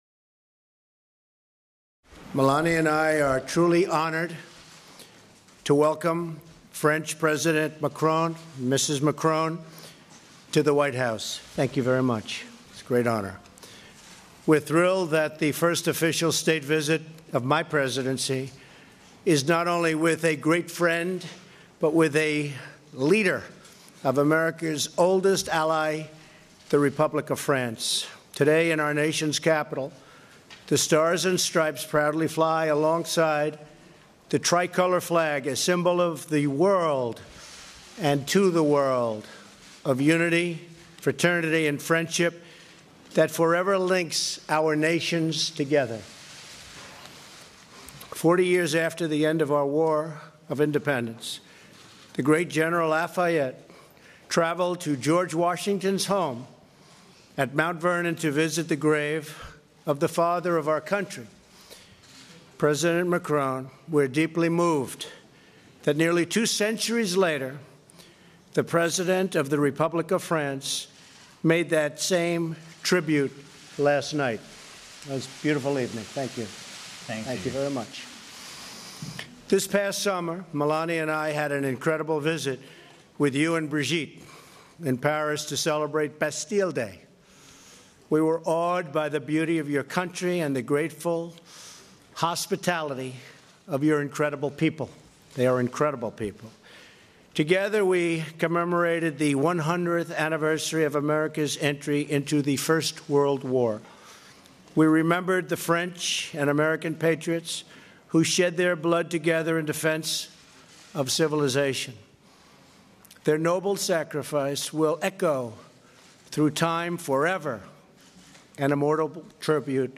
U.S. President Donald Trump and French President Emmanuel Macron hold a joint press conference